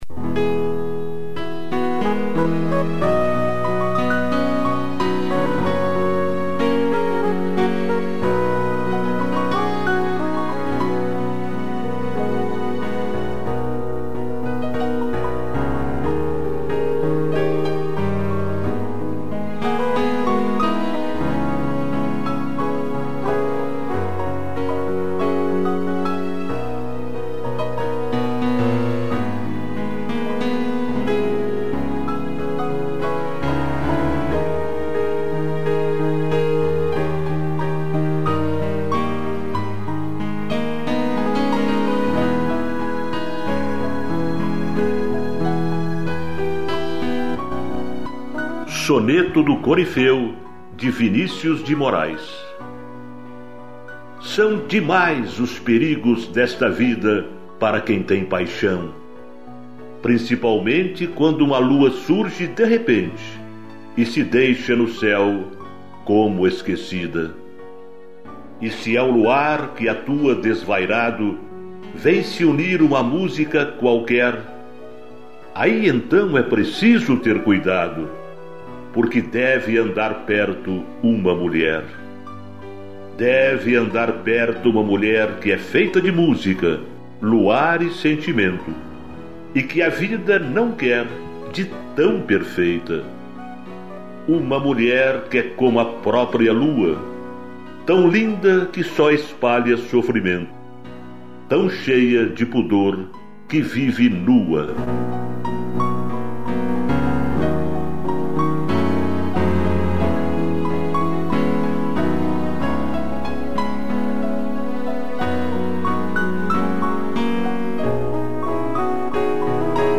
2 pianos, flugle horn e strings